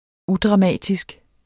Udtale [ ˈudʁɑˈmæˀdisg ]